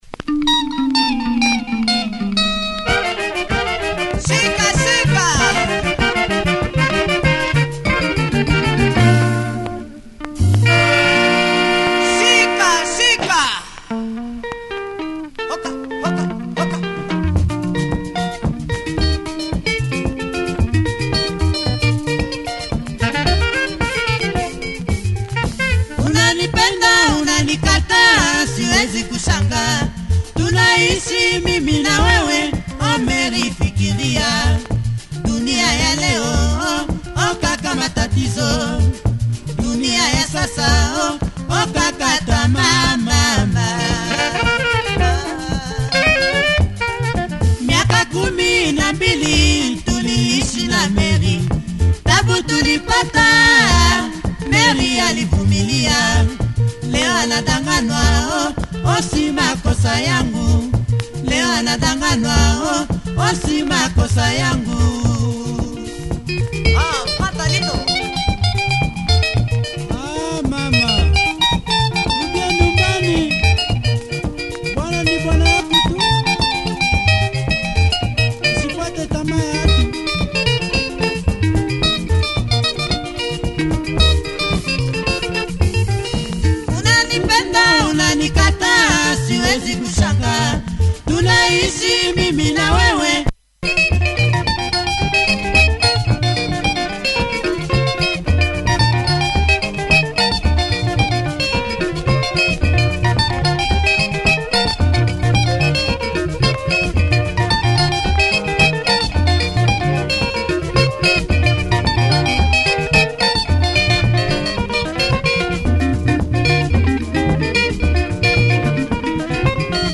lovely horns too … check audio of both sides! https